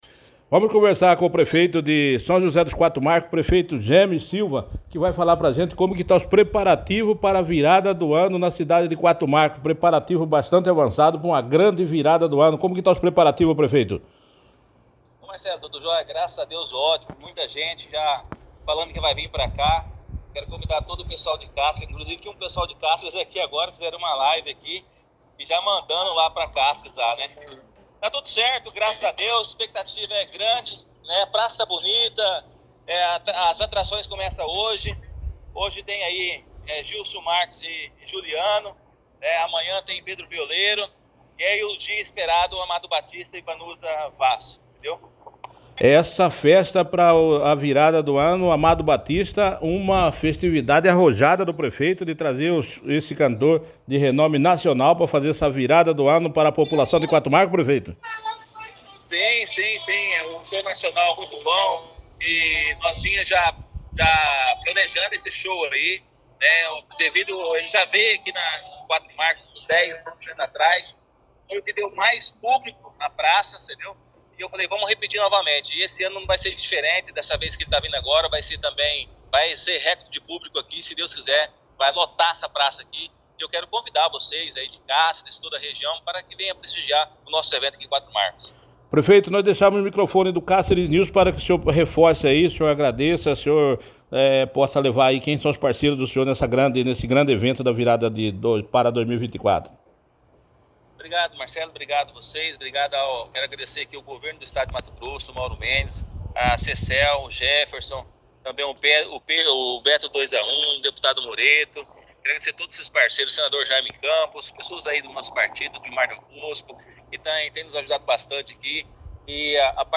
Prefeito de São José dos Quatro Marcos concede entrevista e comenta sobre réveillon
O Prefeito de São José dos Quatro Marcos, Jamis Silva concedeu uma entrevista ao site Cáceres News e comentou sobre o réveillon que vai contar com apresentação do renomado cantor Amado Batista.